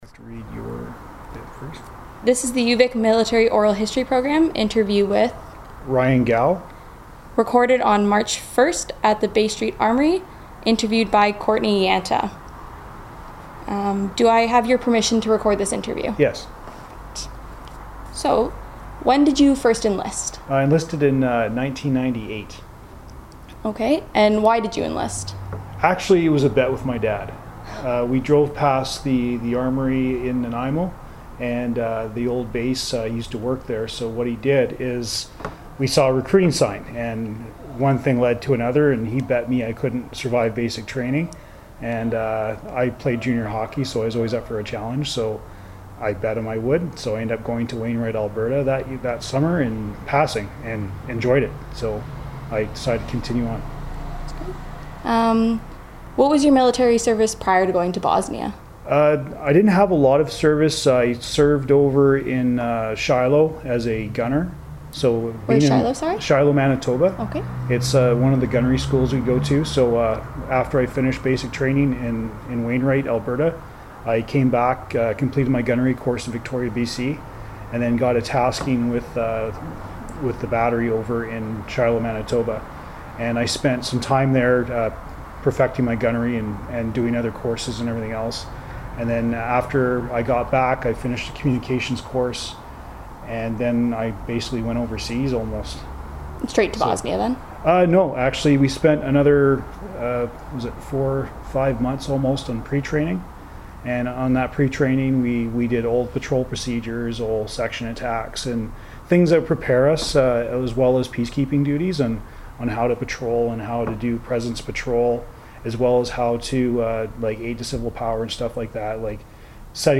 Genre interviews oral histories
Interview recorded in digital format for UVic Special Collections in 2016.